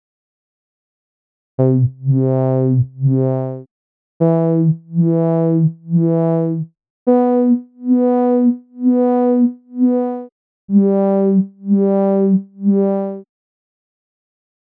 Je gebruikt een sawtooth of squarewave-oscillator in combinatie met een lowpass-filter.
c. LFO
Maak nu een LFO (Low Frequency Oscillator) die de cutoff-frequentie van het [svf~]-filter moduleert.